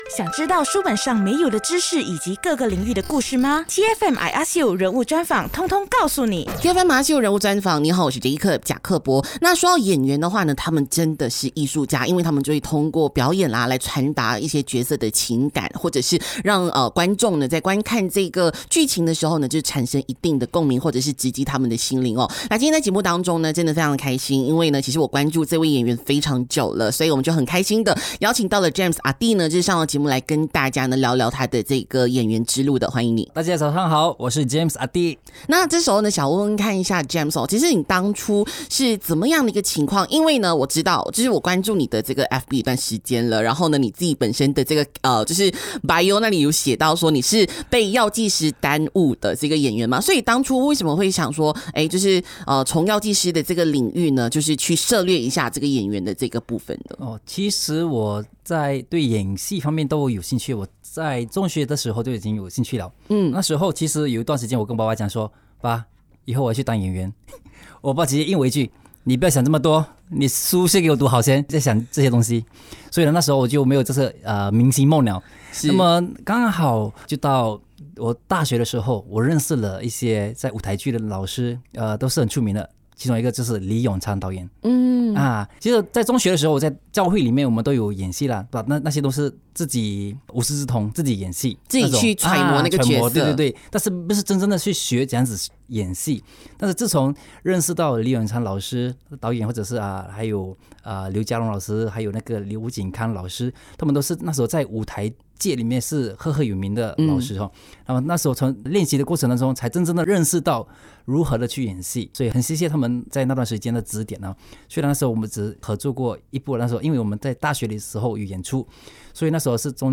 人物专访